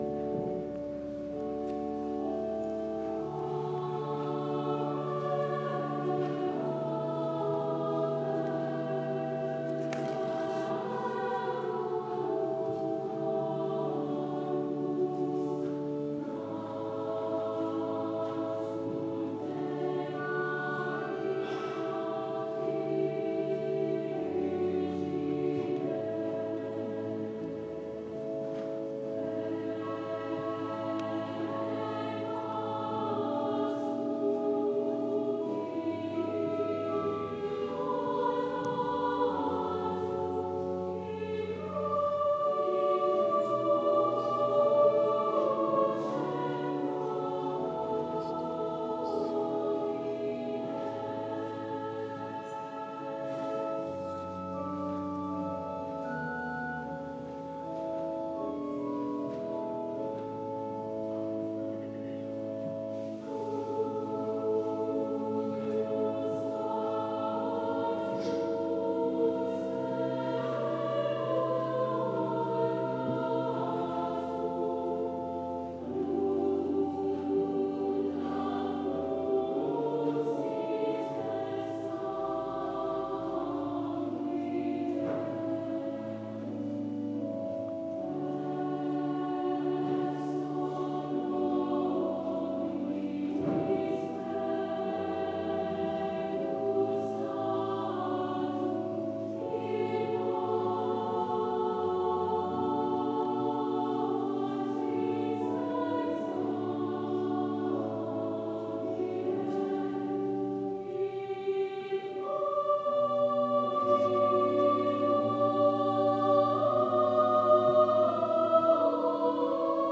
Notre Dame Women's liturgical choir "Ave verum corpus"